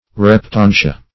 Search Result for " reptantia" : Wordnet 3.0 NOUN (1) 1. lobsters ; crabs ; [syn: Reptantia , suborder Reptantia ] The Collaborative International Dictionary of English v.0.48: Reptantia \Rep*tan"ti*a\ (r?p-t?n"sh?-?), n. pl.